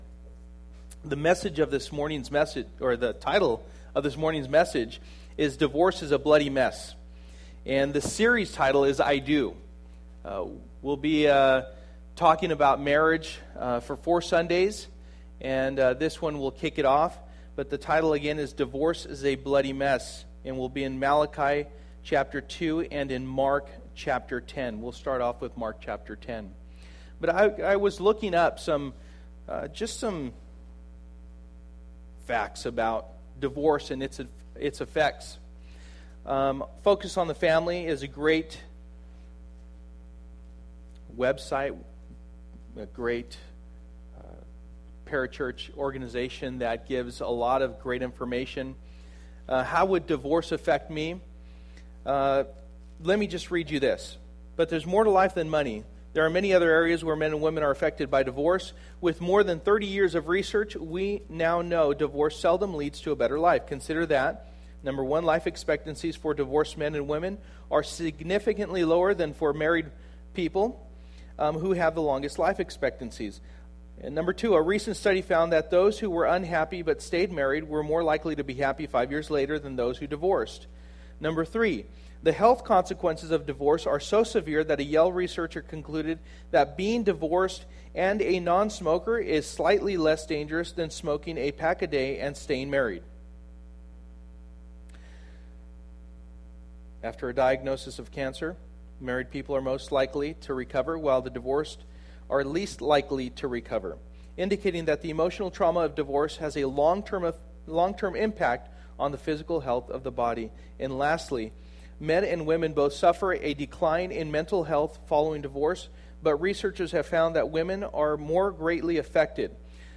I Do Passage: Mark 2:13-16 Service: Sunday Morning %todo_render% « Easter 2012